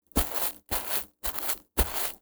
step.wav